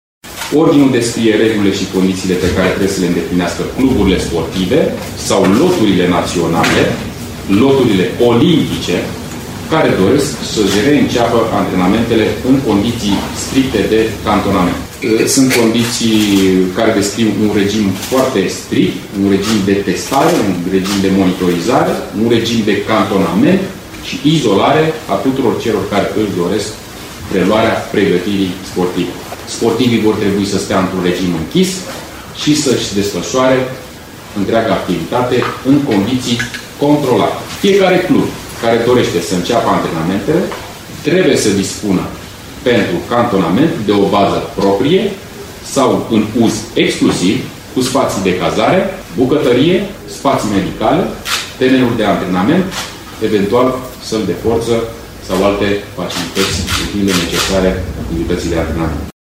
Ministrul Tineretului și Sportului, Ionuț Stroe, a subliniat că încă nu se poate vorbi despre reluarea competițiilor, ci exclusiv de faza de pregătire. Într-o conferință de presă susținută astăzi, el a precizat că sportivii pot reveni la antrenamente, după 15 mai, în condiții stricte, și că, deocamdată, nu se poate vehicula o dată pentru reluarea competițiilor.